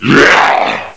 assets/psp/nzportable/nzp/sounds/zombie/a7.wav at main
PSP/CTR: Also make weapon and zombie sounds 8bit